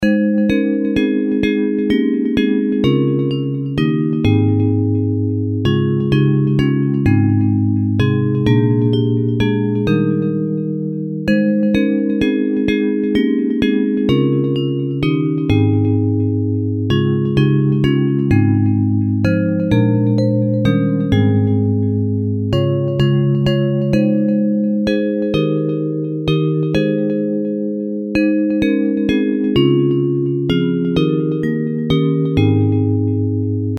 Traditional Hymns
Bells Version